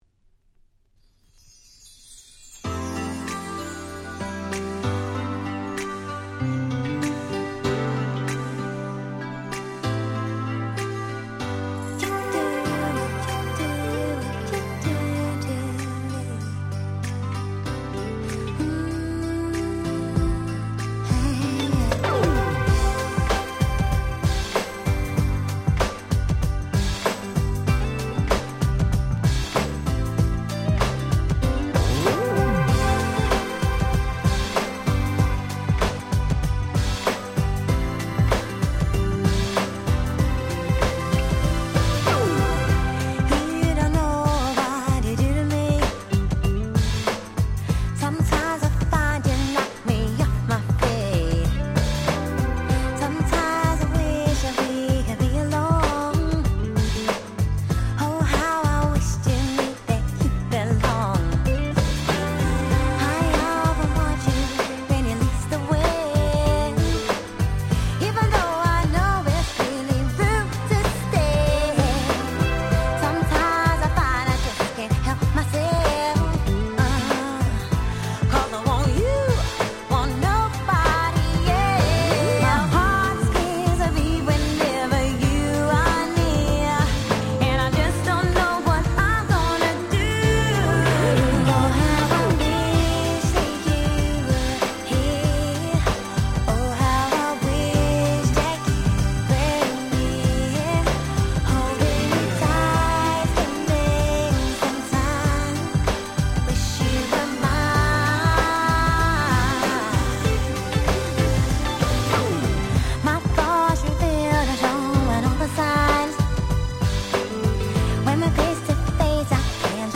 【Media】Vinyl LP